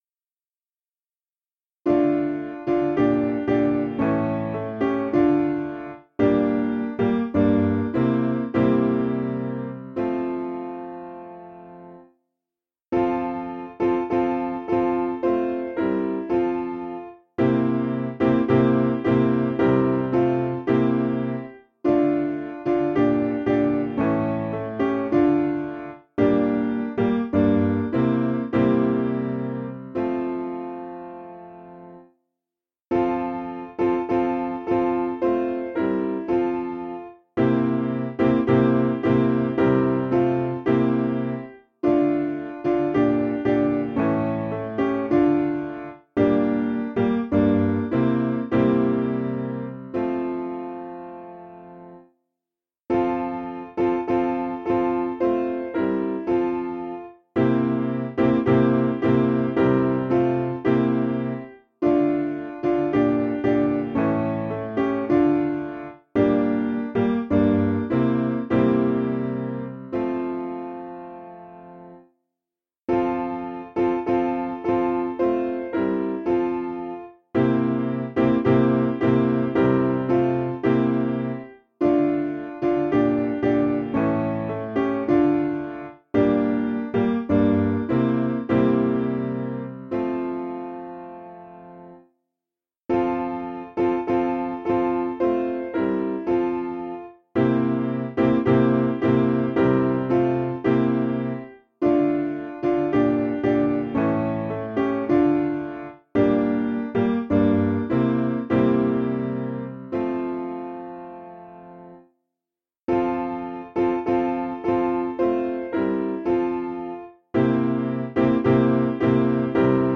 Information about the hymn tune EVELYN (Sullivan).
Key : G